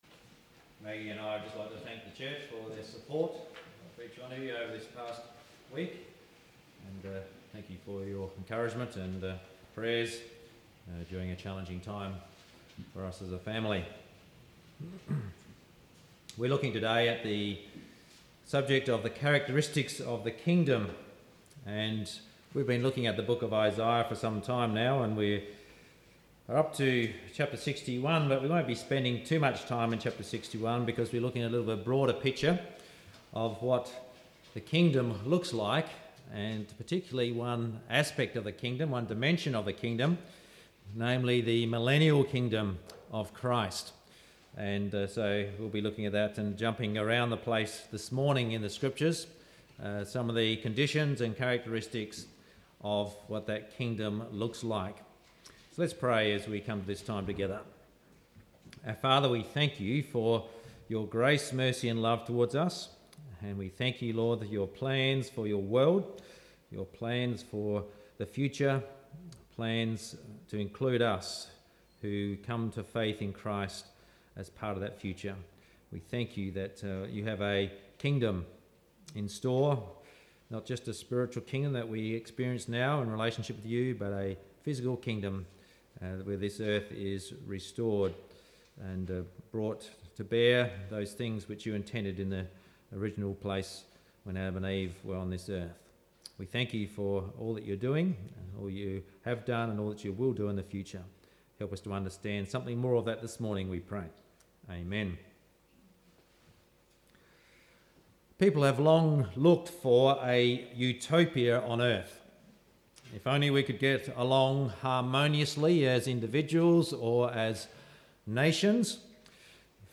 Sunday Service Audio from 20/05/18